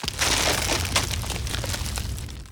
SFX / Spells / Ice Wall 1.ogg
Ice Wall 1.ogg